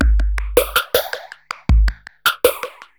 Ambient / Loop / DRUMLOOP053_AMBNT_160_X_SC3.wav